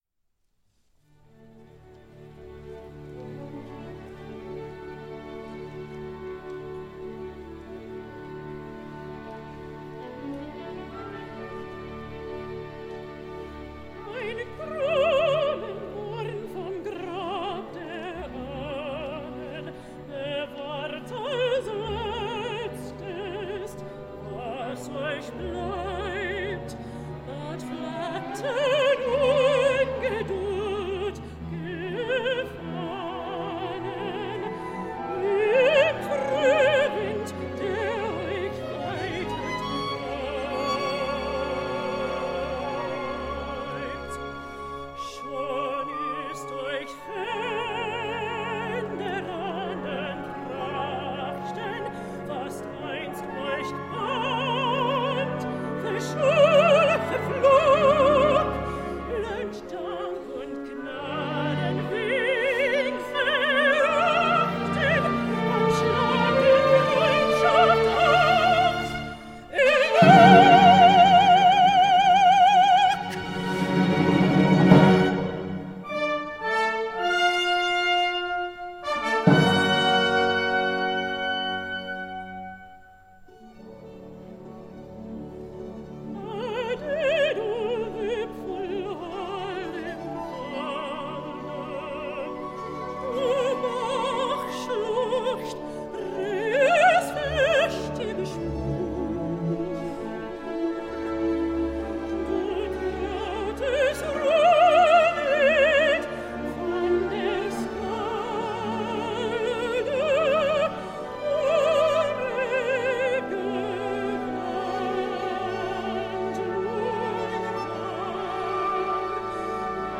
Mezzosoprano
Live-performance from 28.1.2020